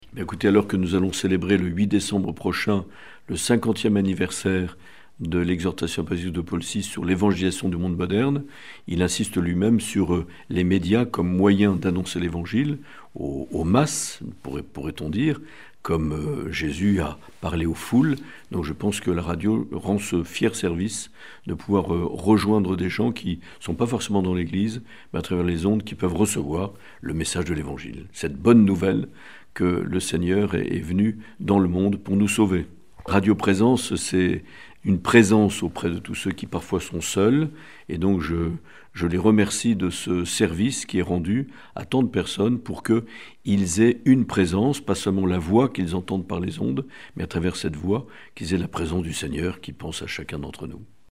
Écouter le message de Mgr Marc Aillet